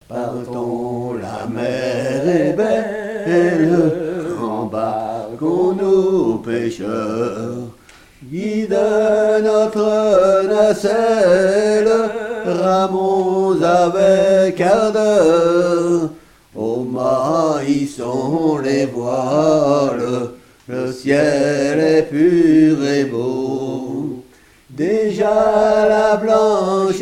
danse : valse
chansons dont de marins
Pièce musicale inédite